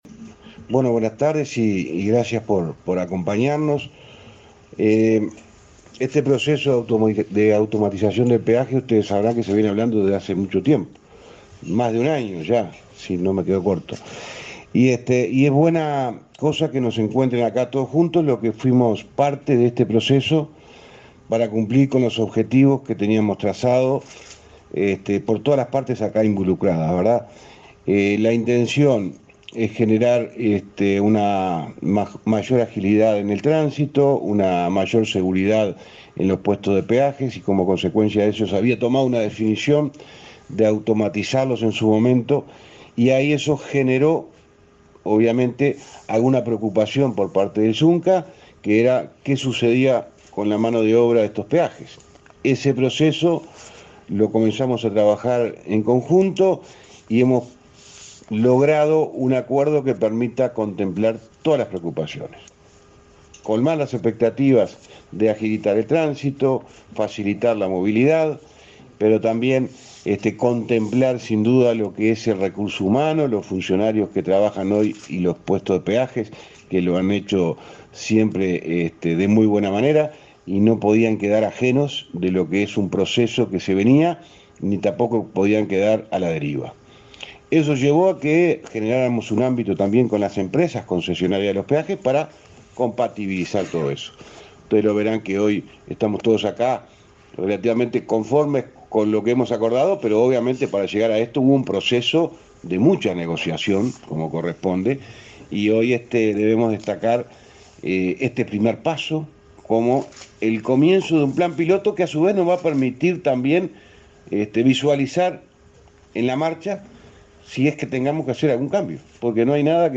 Palabras del ministro de Transporte, José Luis Falero 16/06/2022 Compartir Facebook X Copiar enlace WhatsApp LinkedIn Este jueves 16, el ministro de Transporte y Obras Públicas, José Luis Falero, se expresó en conferencia de prensa para informar sobre el proceso de automatización de los peajes.